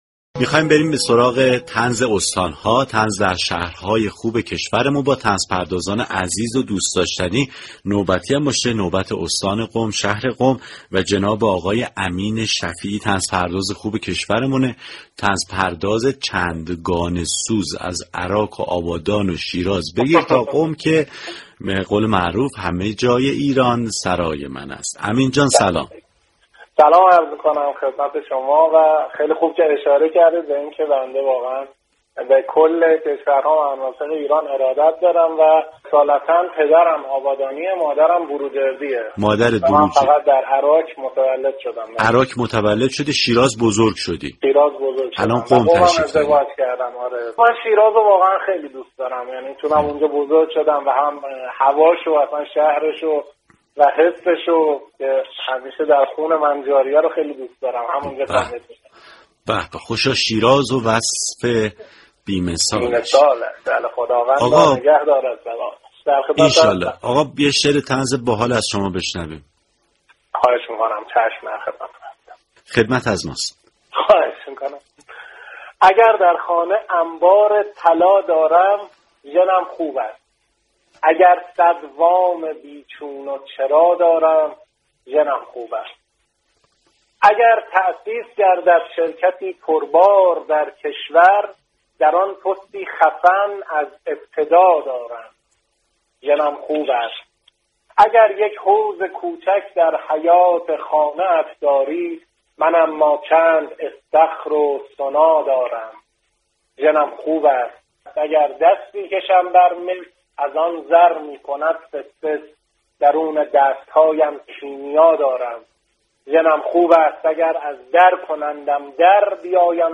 گفتگوی